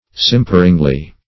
Simperingly \Sim"per*ing*ly\, adv.